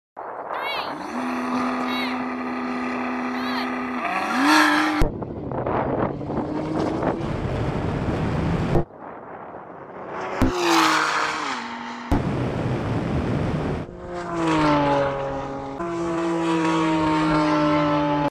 890 hp Porsche 918 Spyder sound effects free download
890 hp Porsche 918 Spyder v 990 hp uned Turbo S